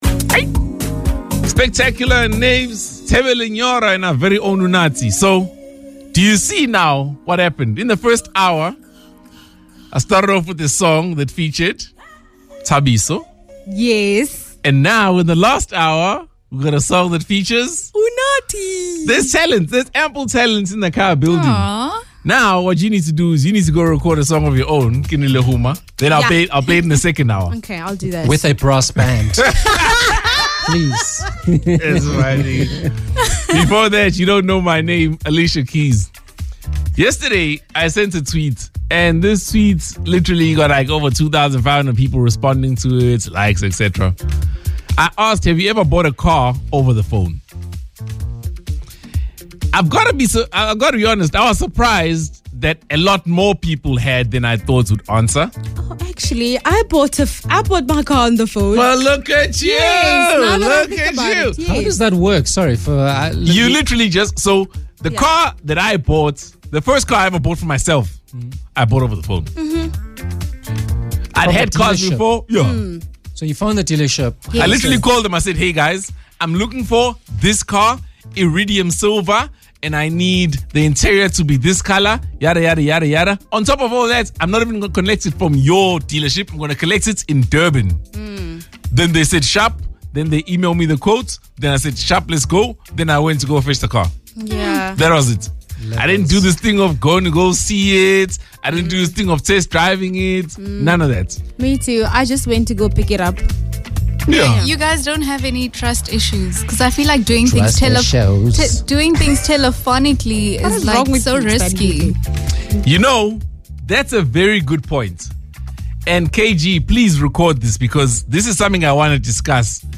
THIRD-HOUR-BANTER-26-OCT-21.mp3